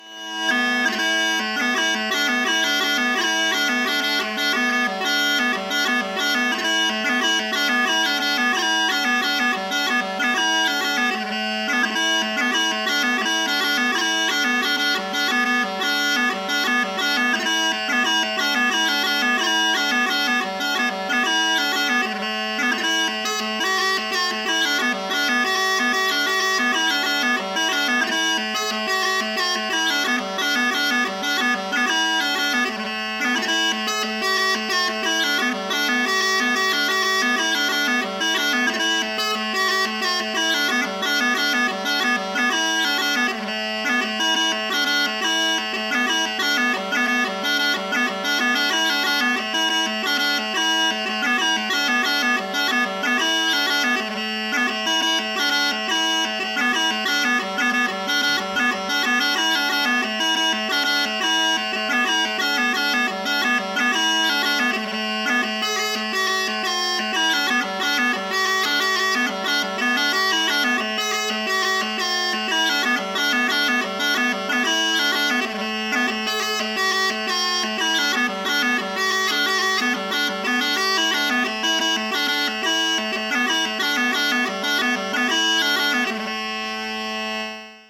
Hornpipe